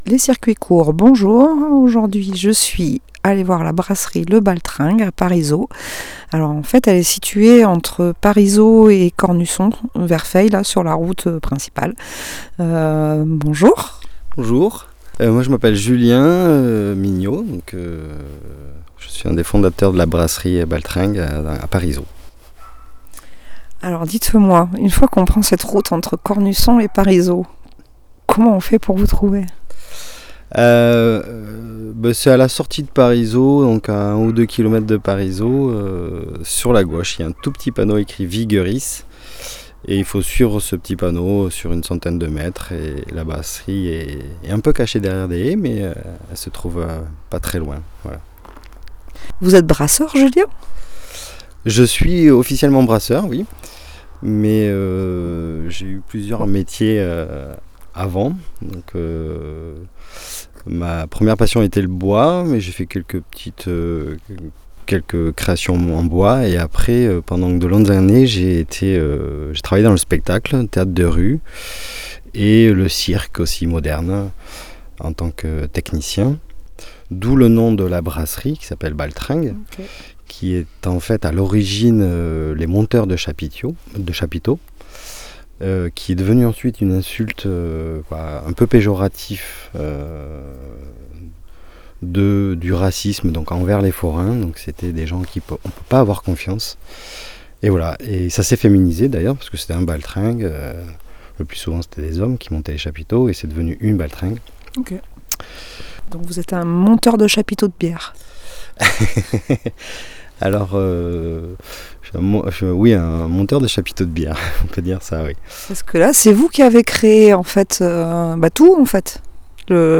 Visite de la brasserie Baltringue de Parisot.